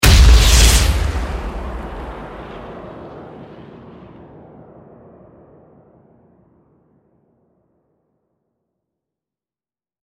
Запуск ракеты из базуки